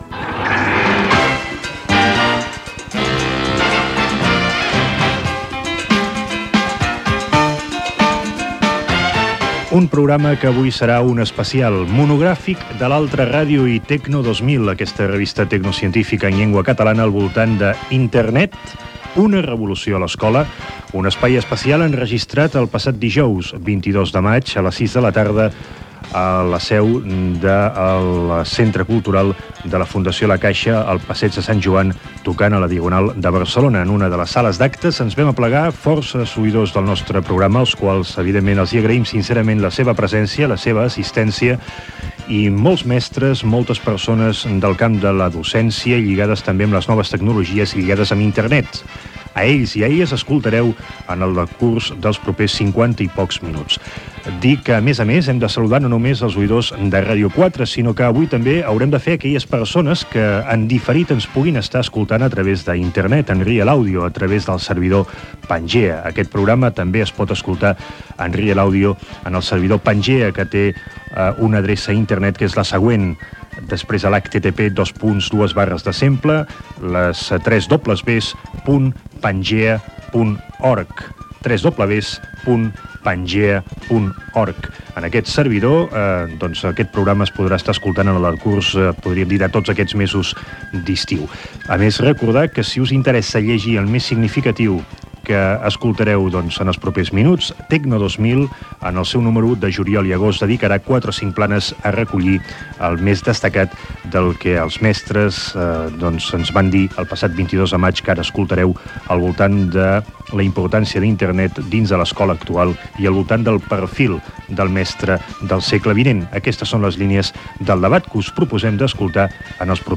Inici de l'edició "Internet: una revolució a l'escola" amb esment a l'audiència de Pangea i la intervenció de Ramon Fons, alcalde de Callús.
Divulgació
Aquesta edició de "L'altra ràdio" de Ràdio 4, enregistrada cara al públic a la seu del Centre Cultural de la Fundació la Caixa de Barcelona, el 22 de maig de 1997 i emesa en FM el dia 31, es va poder escoltar a Internet a la carta, en Real Audio ,durant tot l'estiu al web de l'Associació Pangea.